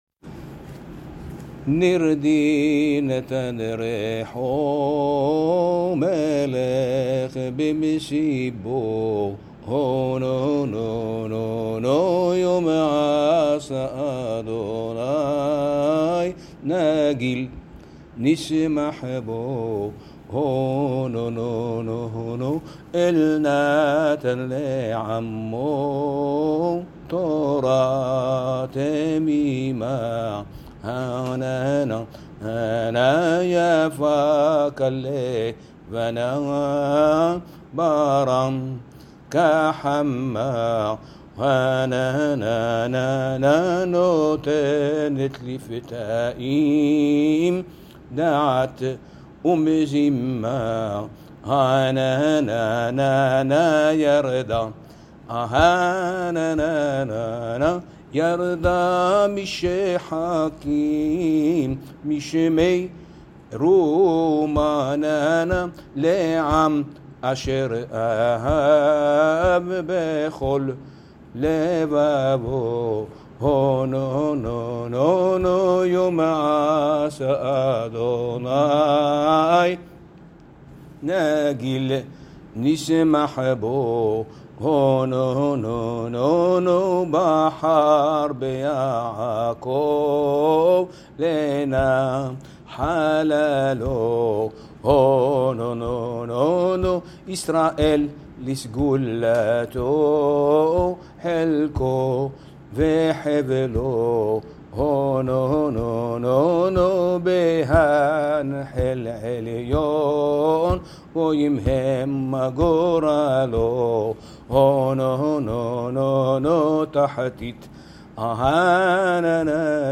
תיעוד פיוטי קהילת צפרו לחג השבועות – קהילת צפרו / סְפְרוֹ